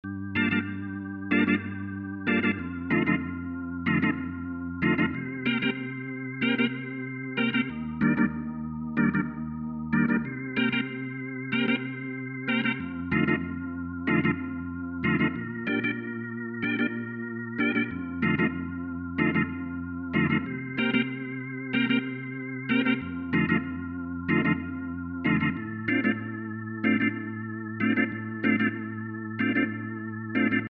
La rythmique : Orgue
La deuxième est décalée d'une croche puis envoyée dans le Chorder avec les correspondances notes/accords citées plus haut.
Elle passe ensuite dans un écho réglé à la noire pointée (durée entre le son original et le son répété), avec 2 répétitions.
Da_skouer-Rythmic-Orgue.mp3